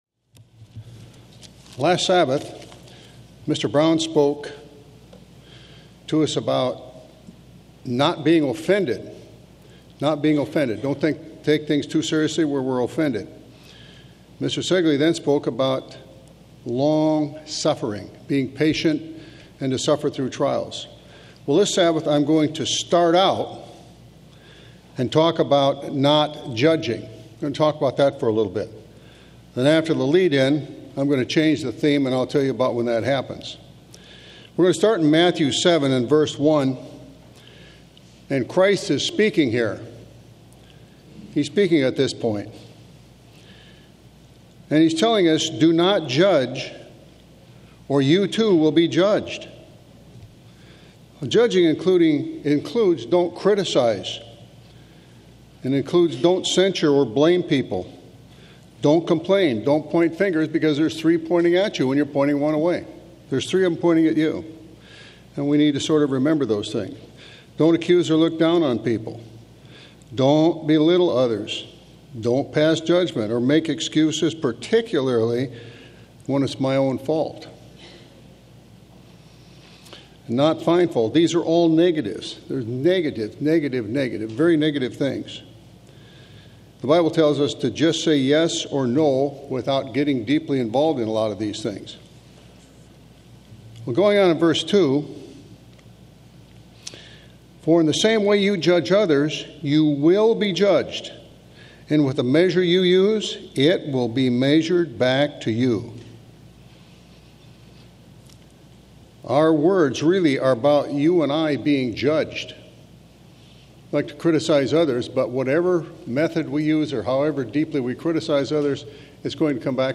This sermon offers several biblical examples that show us how to express thankfulness to God.